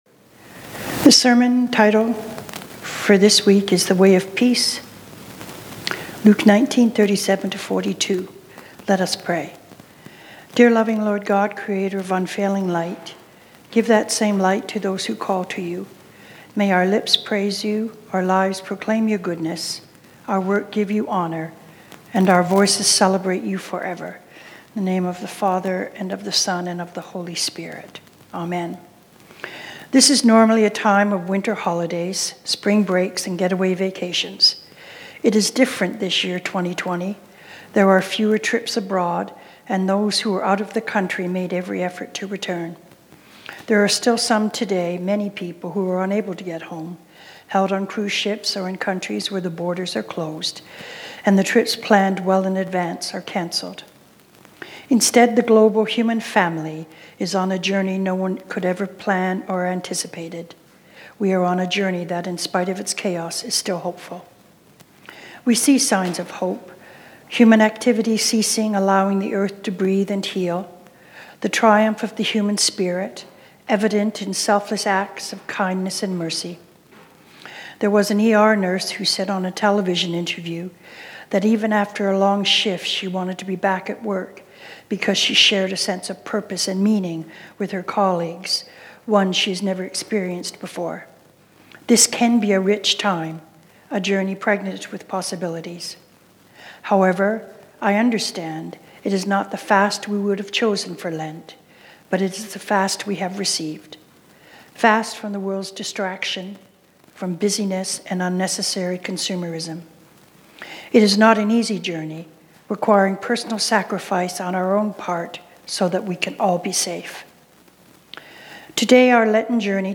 Sermons | St. David and St. Paul Anglican Church